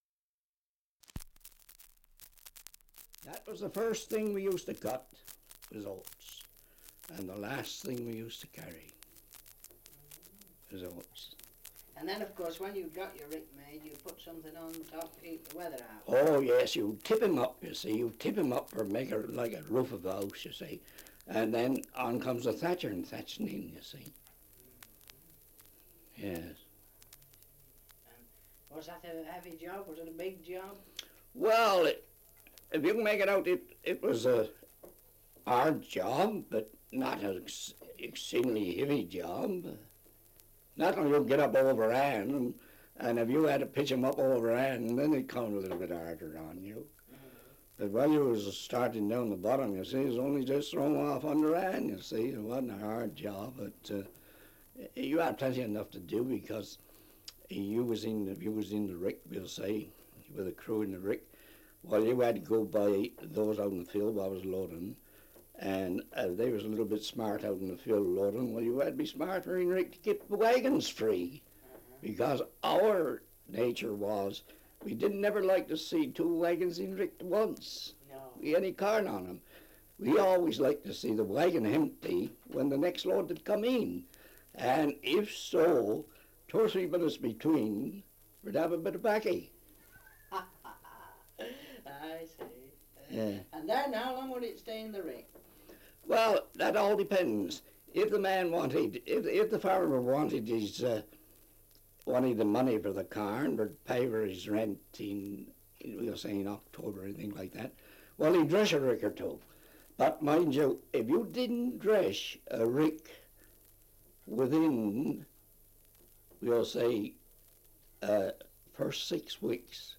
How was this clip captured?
Survey of English Dialects recording in Portesham, Dorset 78 r.p.m., cellulose nitrate on aluminium